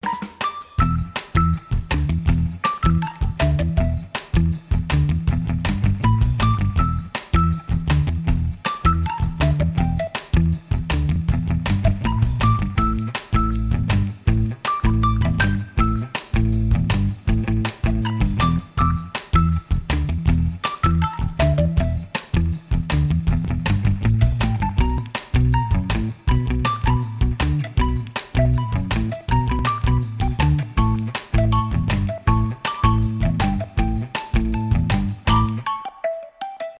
инструментальные , танцевальные , без слов